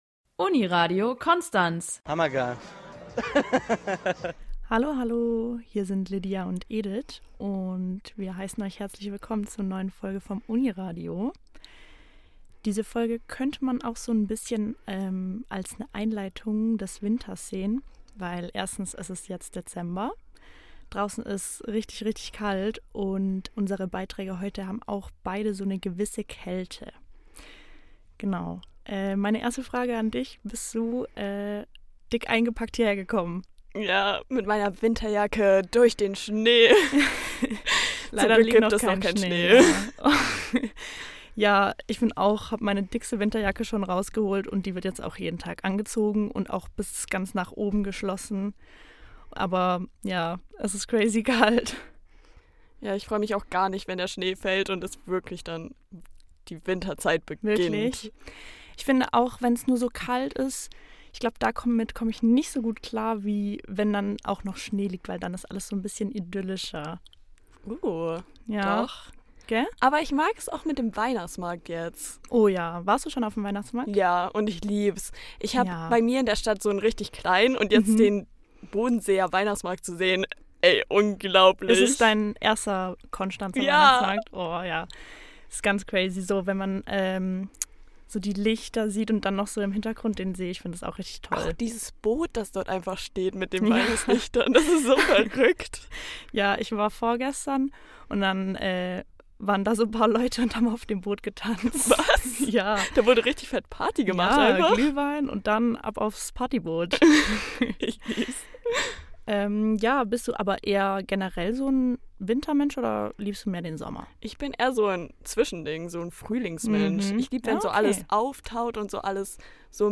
Genießt die neue Hörspielfolge – bis zum nächsten Mal!